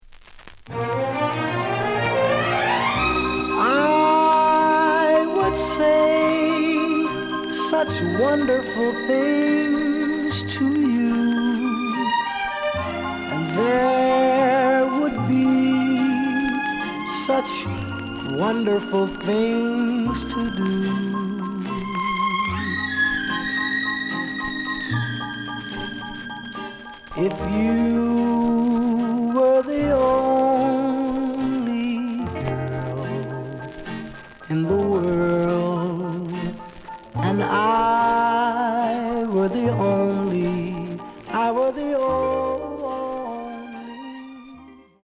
The listen sample is recorded from the actual item.
●Genre: Soul, 60's Soul
Some noise on both sides.